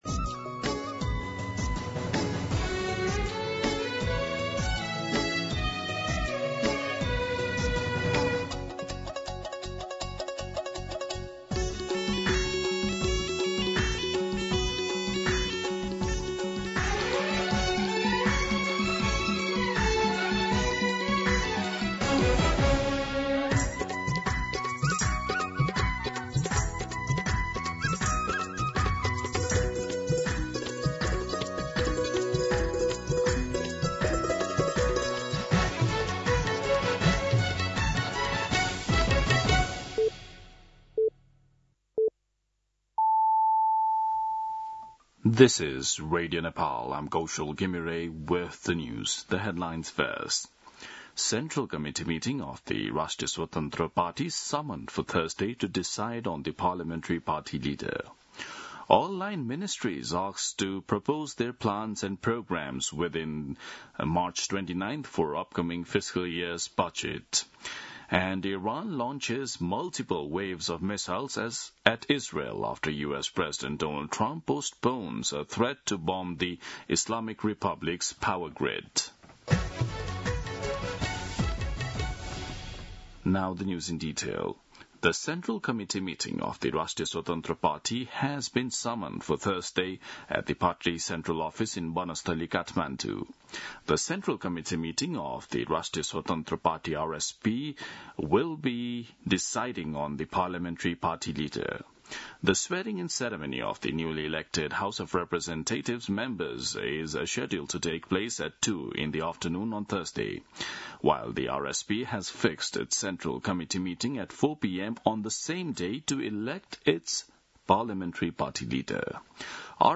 दिउँसो २ बजेको अङ्ग्रेजी समाचार : १० चैत , २०८२
2pm-English-News-10.mp3